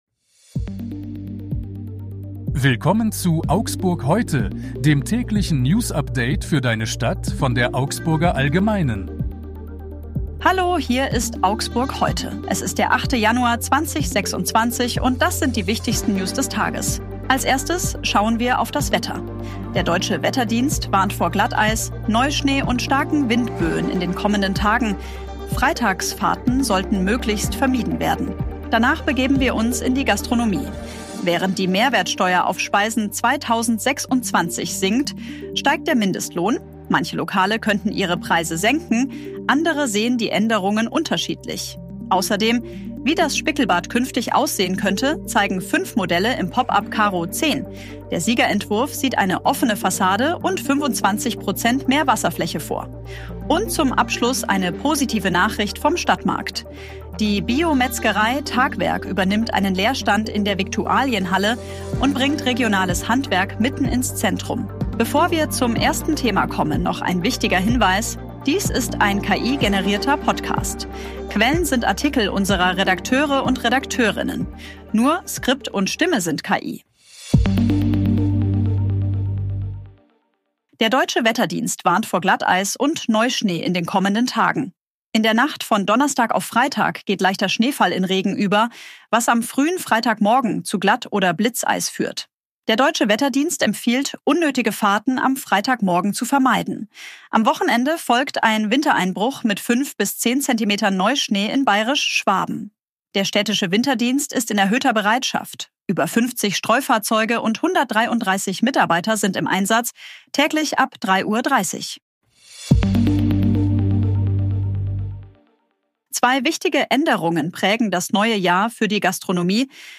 Skript und Stimme sind KI.